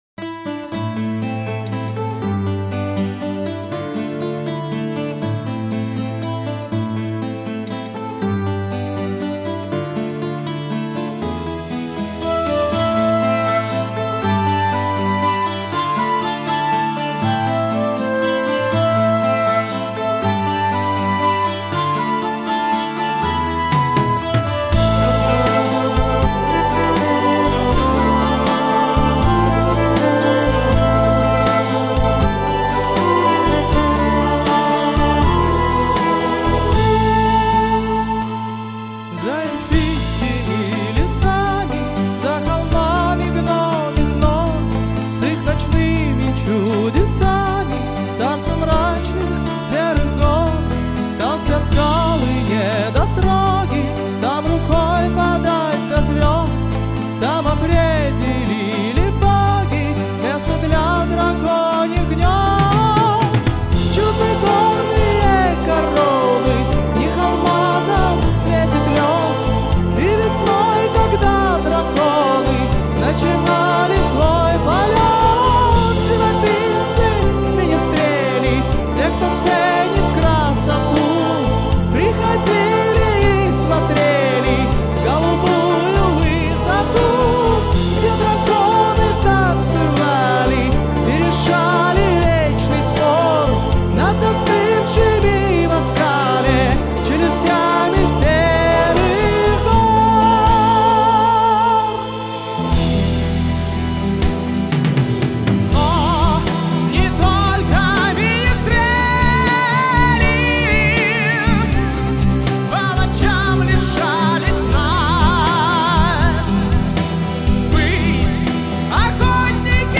Power Metal